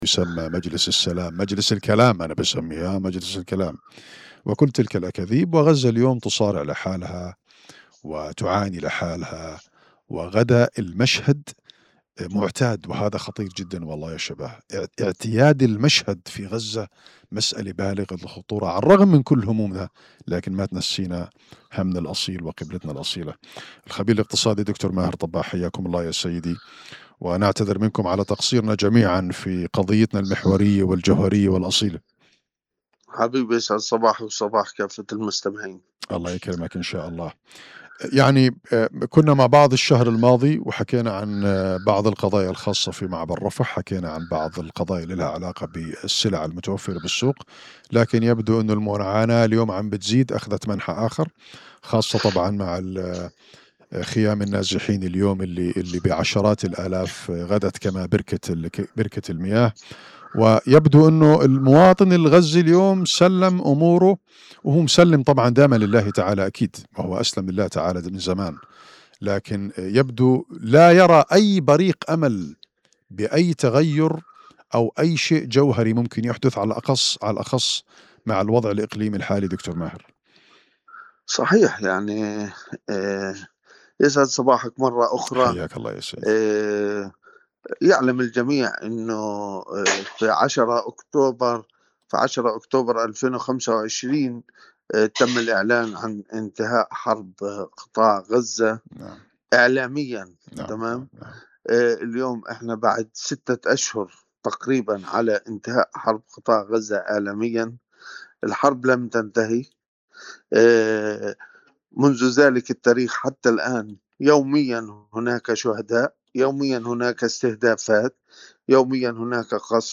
مقابلة على اثير الرقيب